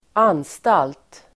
Uttal: [²'an:stal:t]